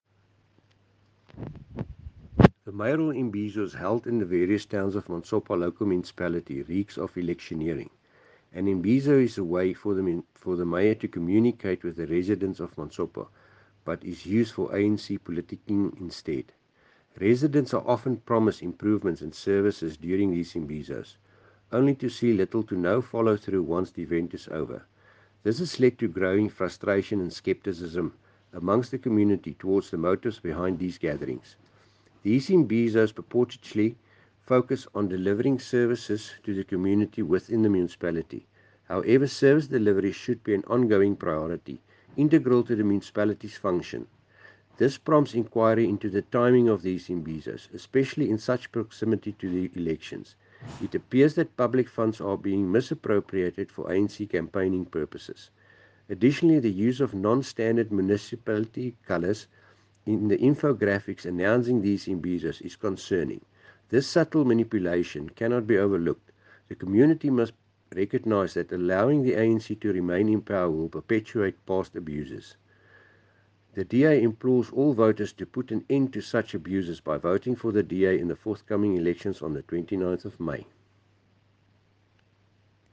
English and Afrikaans soundbites by Cllr Dewald Hattingh and Sesotho by Karabo Khakhau MP.